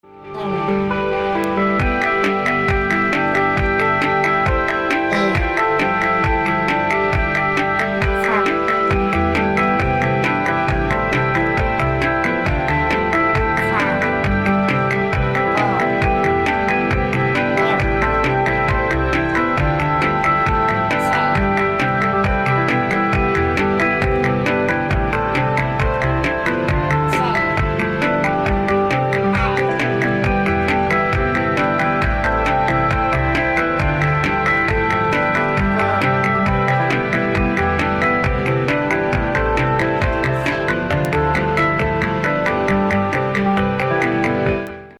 and haunting vocal tracks